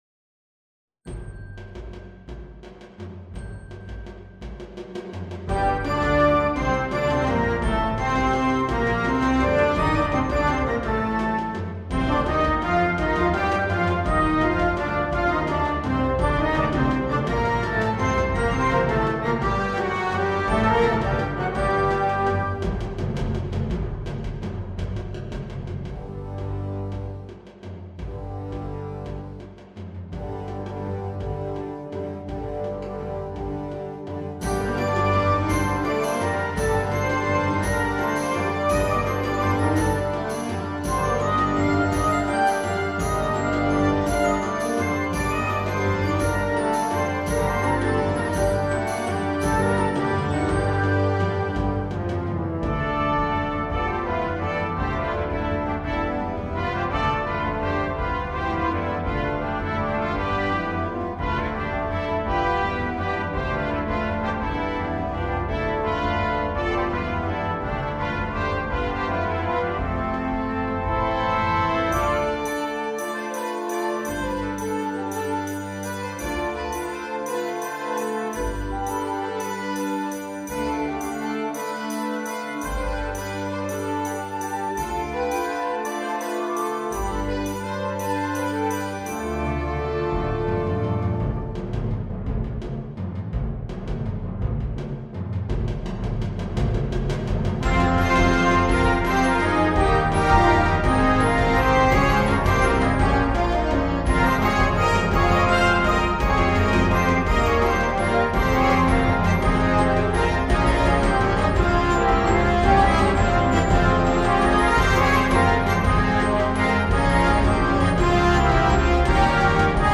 Gattung: Suite